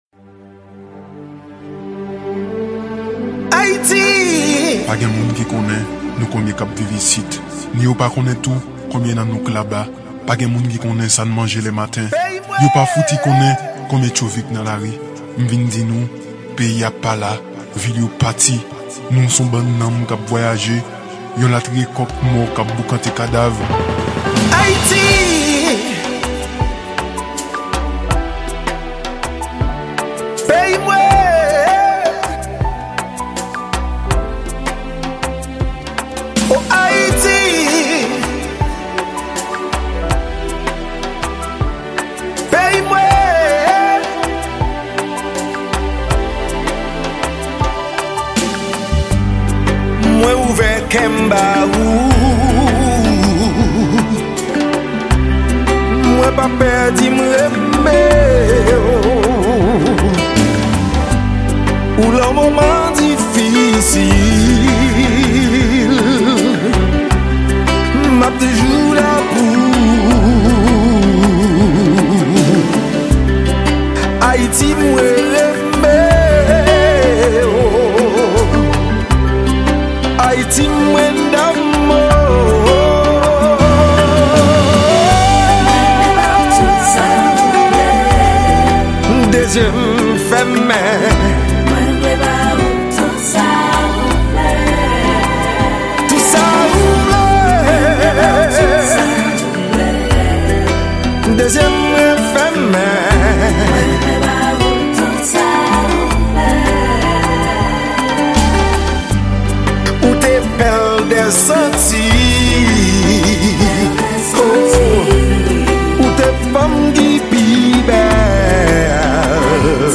Genre: KONPA.